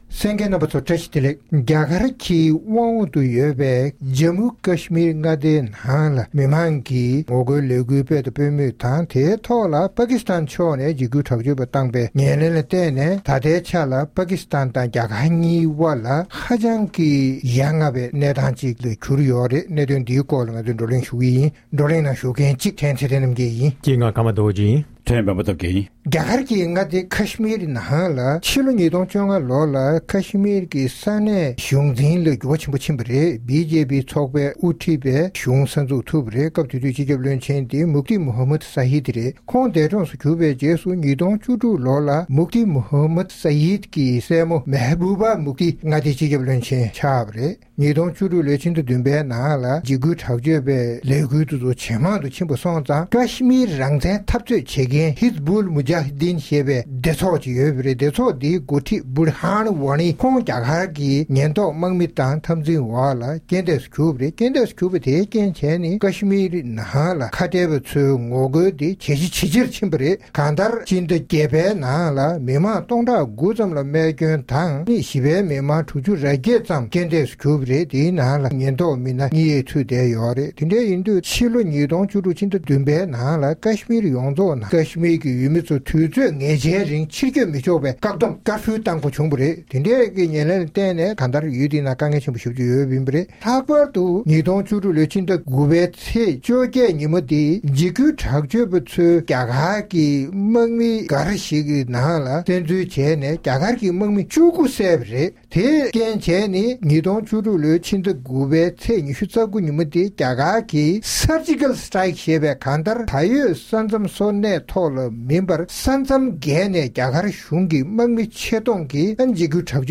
རྒྱ་གར་དང་པ་ཀིསྟན་དབར་ཀཤ་མིར་མངའ་སྡེའི་ནང་གི་དཀའ་རྙོག་ལ་སོགས་ཀྱི་སྐོར་རྩོམ་སྒྲིག་འགན་འཛིན་རྣམ་པས་བགྲོ་གླེང་གནང་བ་ཞིག་གསན་རོགས་གནང་།།